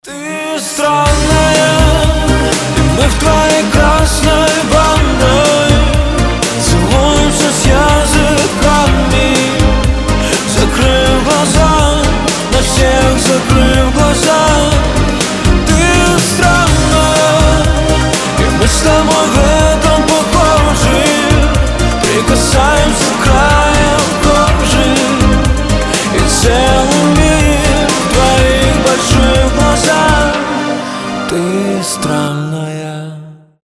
• Качество: 320, Stereo
поп
мужской вокал